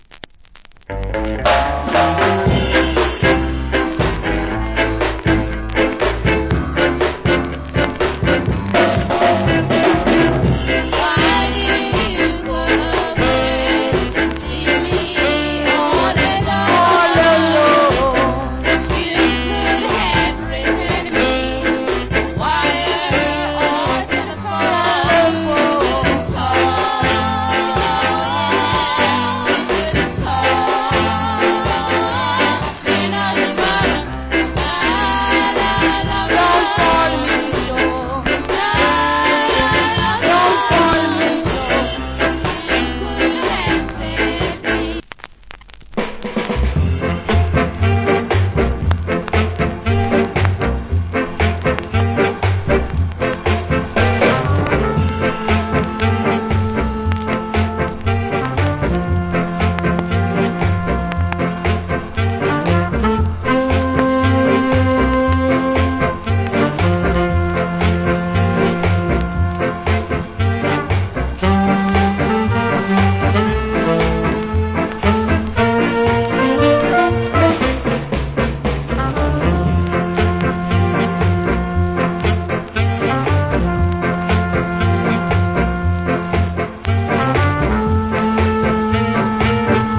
Great Femake Ska Vocal.